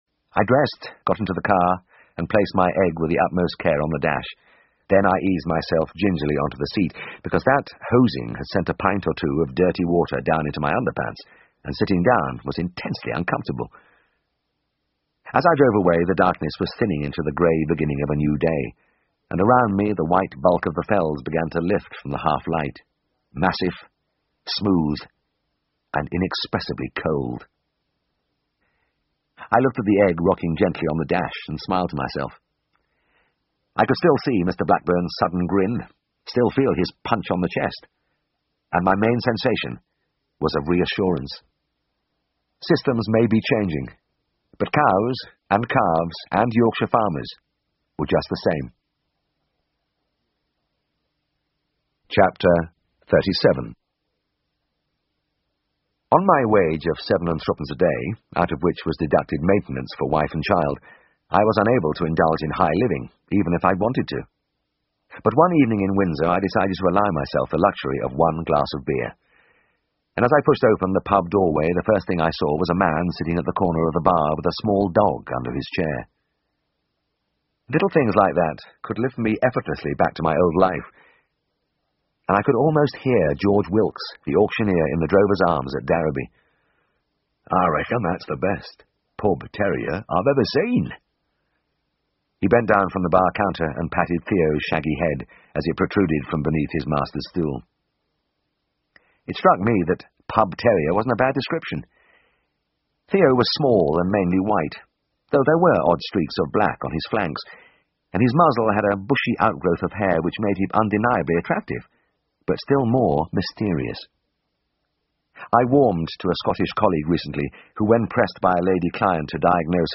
英文广播剧在线听 All Things Wise and Wonderful Chapter 88 听力文件下载—在线英语听力室
在线英语听力室英文广播剧在线听 All Things Wise and Wonderful Chapter 88的听力文件下载,英语有声读物,英文广播剧-在线英语听力室